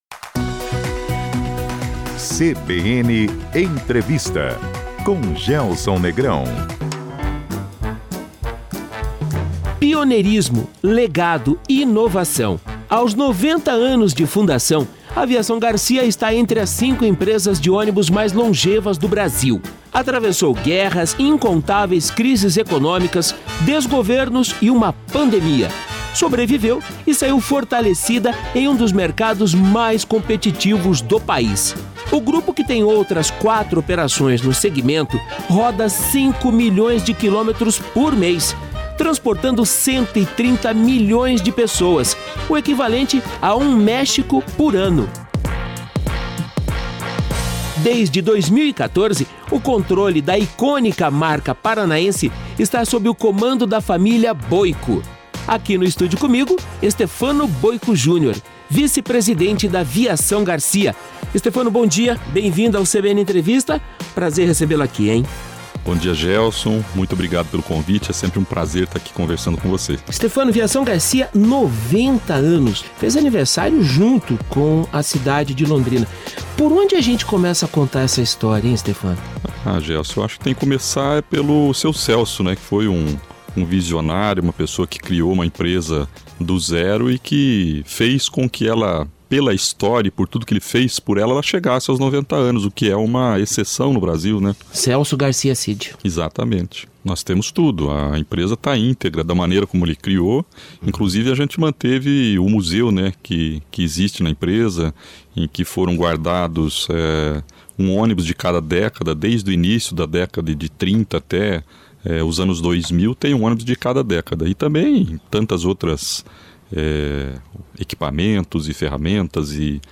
CBN Entrevista: Viação Garcia 90 anos